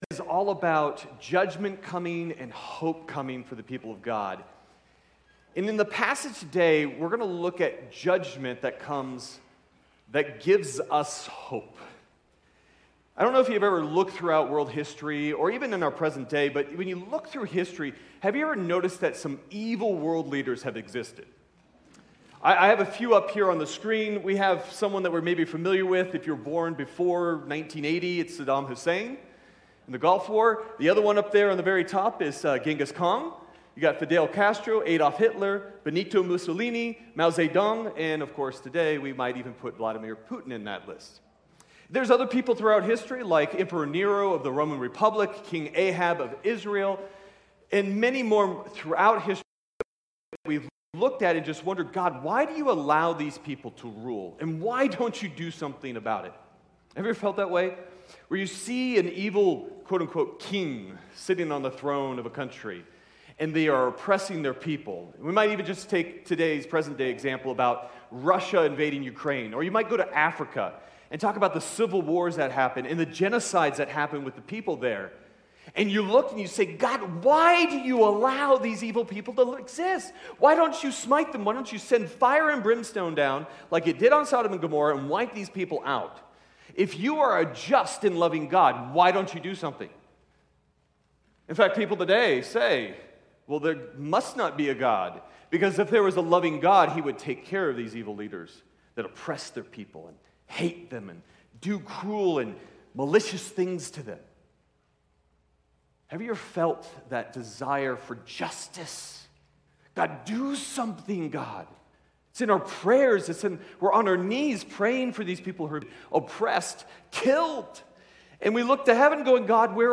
Sermon Detail